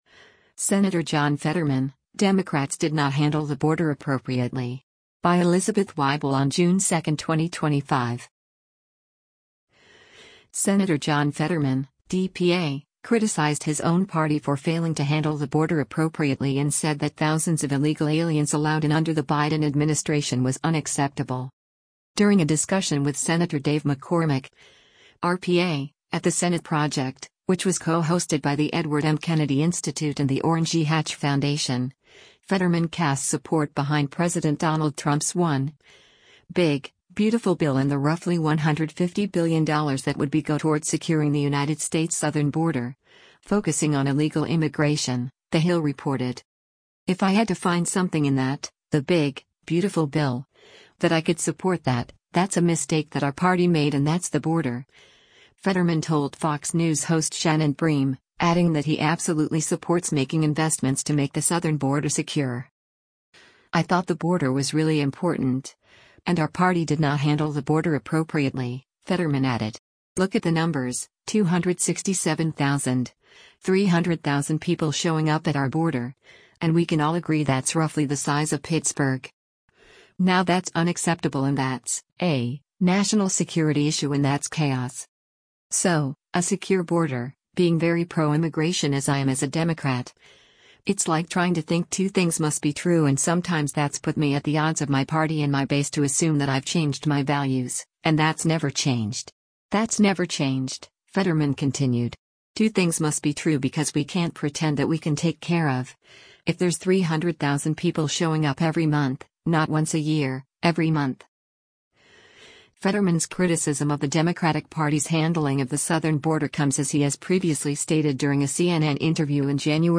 “If I had to find something in that, the Big, Beautiful Bill, that I could support that, that’s a mistake that our party made and that’s the border,” Fetterman told Fox News host Shannon Bream, adding that he “absolutely” supports making investments to make the southern border secure.